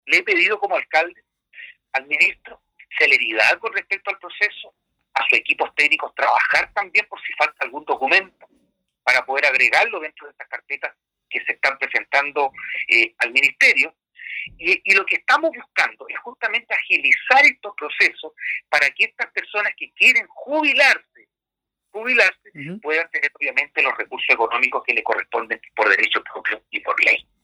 En lo que respecta a los requerimientos de la comuna de Castro en materia educacional, el alcalde Juan Eduardo Vera señaló que se hizo la petición de avanzar de una vez por todas en entregar recursos para que muchos docentes y asistentes de la educación se pueda jubilar y así aliviar la carga financiera de remuneraciones.